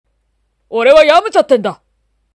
ヤムチャこえまね